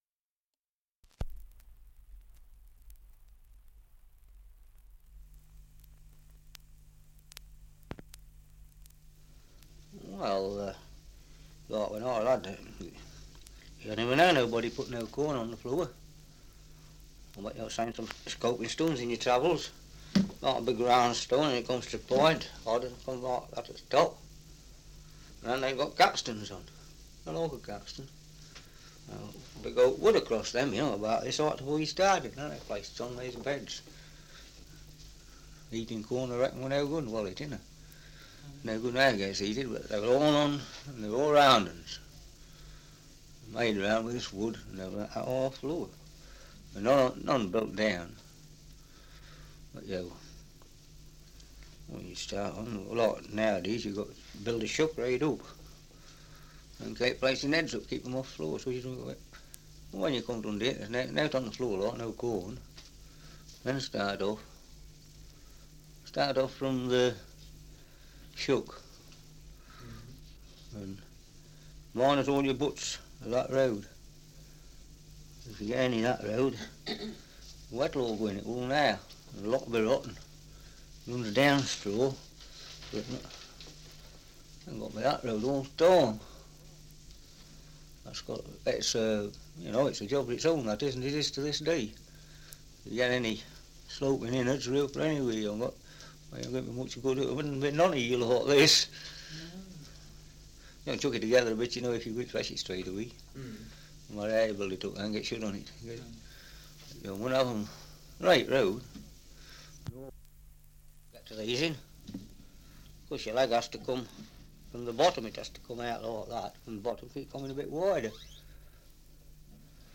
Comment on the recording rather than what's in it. Survey of English Dialects recording in Hoar Cross, Staffordshire 78 r.p.m., cellulose nitrate on aluminium